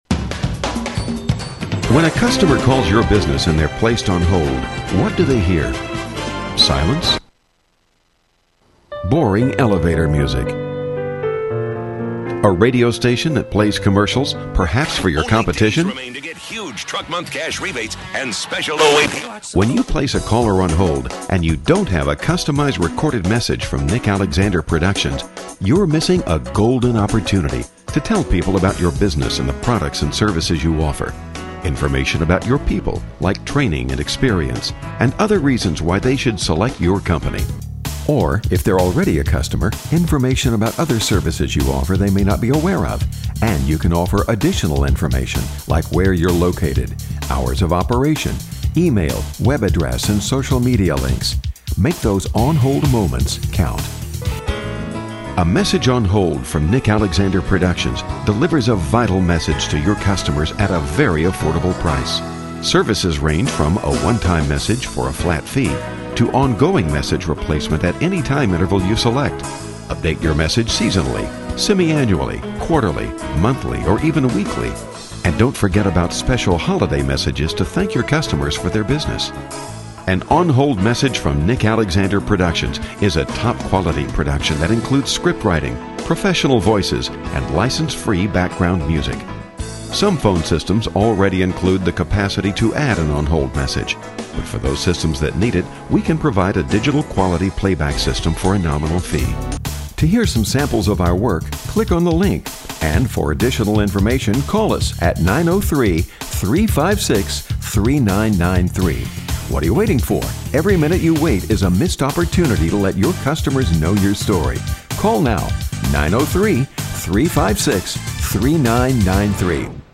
Message on hold
These are available in single or multiple voices, male and/or female, with or without music and sound effects and may be updated as often as necessary.
Message On Hold Demo